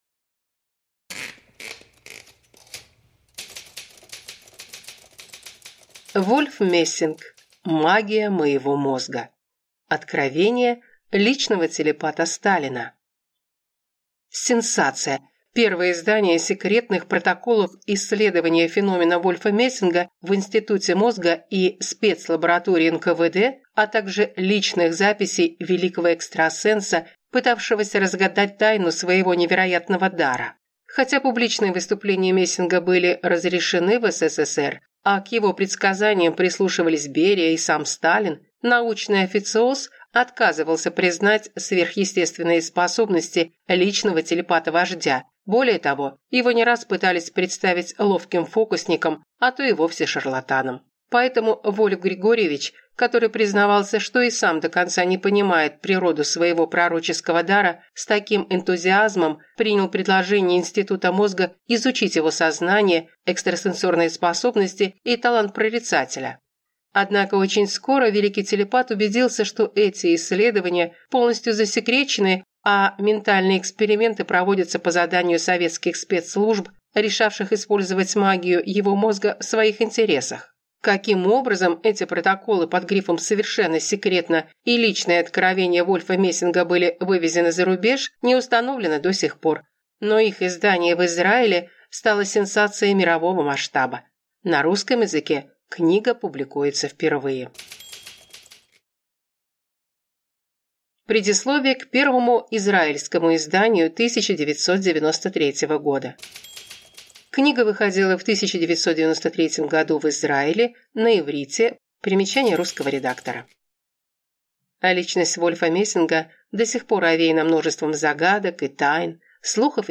Аудиокнига Магия моего мозга. Откровения «личного телепата Сталина» | Библиотека аудиокниг